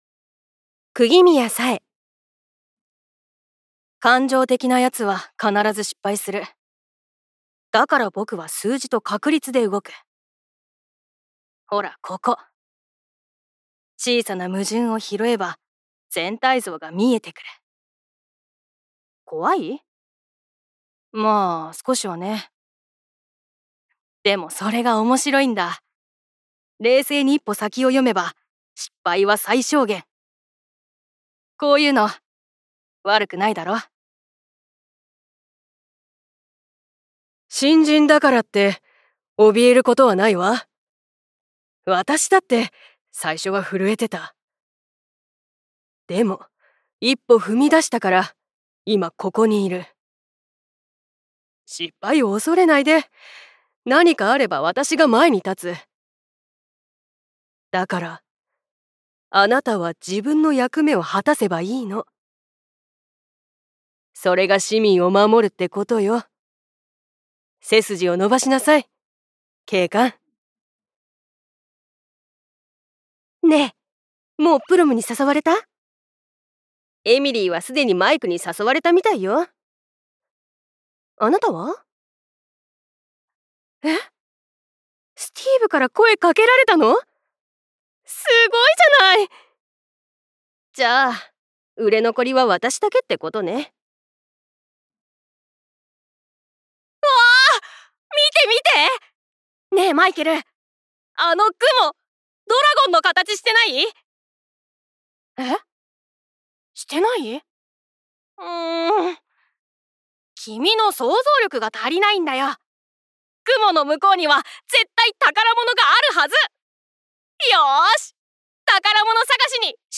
◆台詞